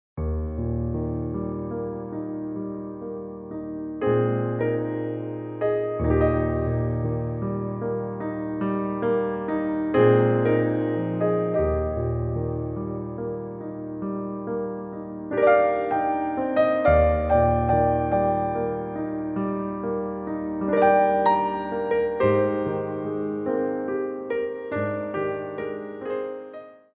Adagio
3/4 (8x8)